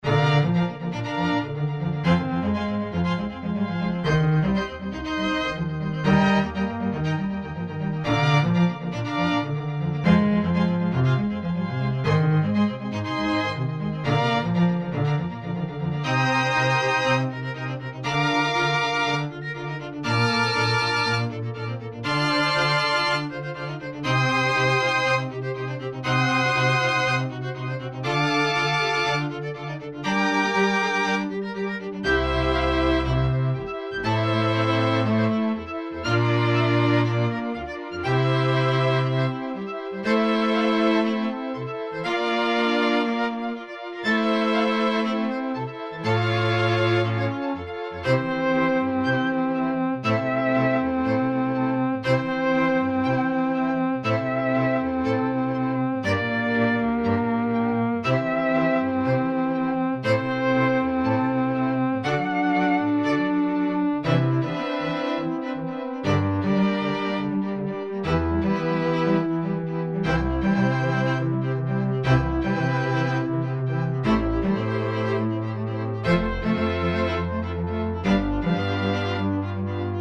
BGM
コントラバス、バイオリン